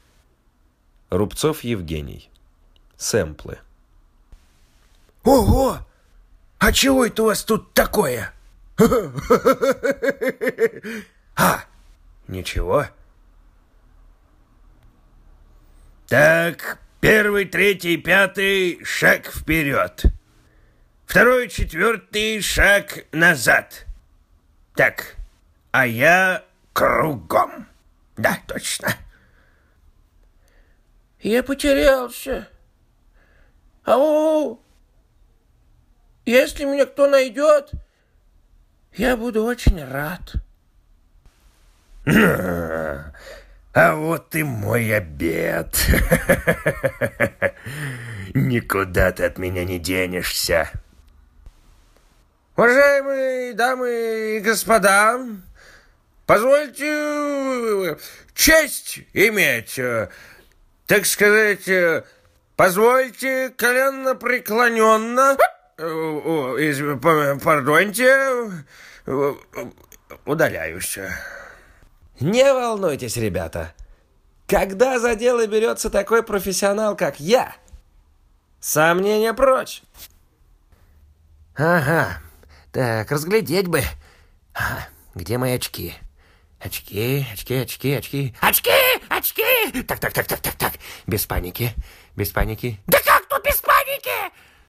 Мужской
Баритон